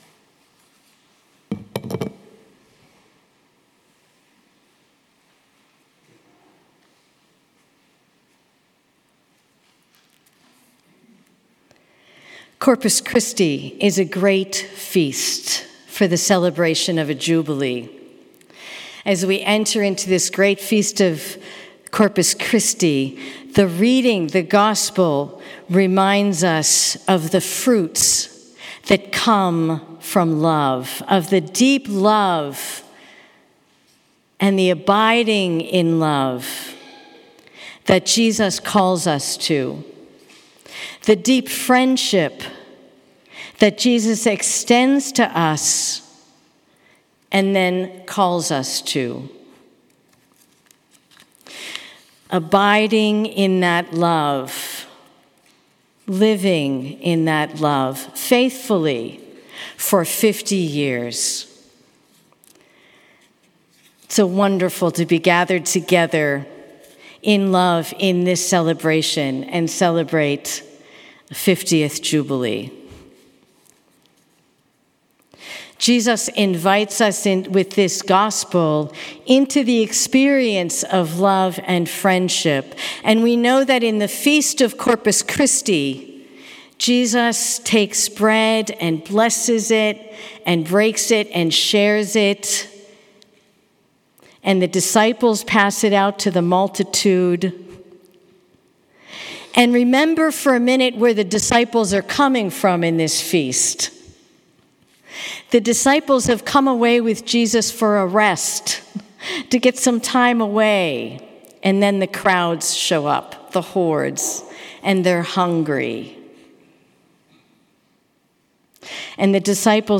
The Benedictine community celebrated their three Golden Jubilarians at Evening Praise on June 21.
Many friends, family, and oblates joined the celebration in chapel and enjoyed the reception that followed.